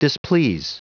Prononciation du mot displease en anglais (fichier audio)